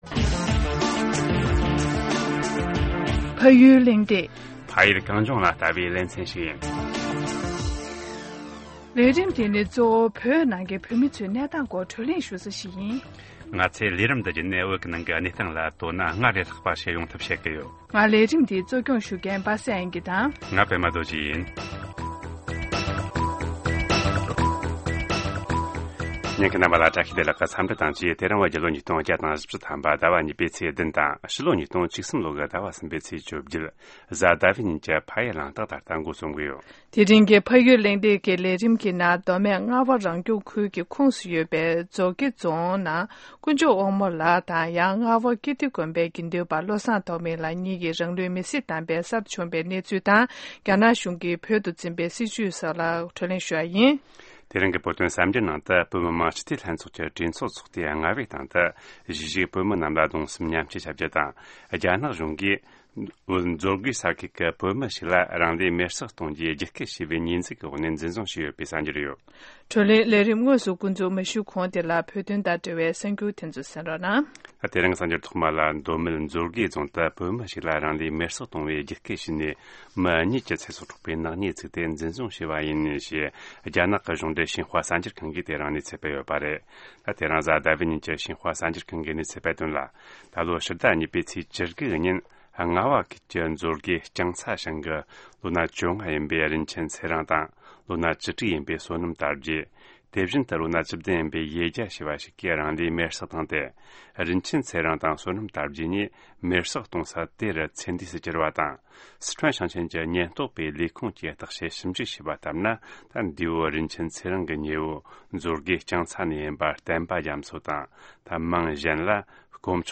ཕ་ཡུལ་གླེང་སྟེགས་ནང་གནས་འདྲི་ཞུས་པ་རེད།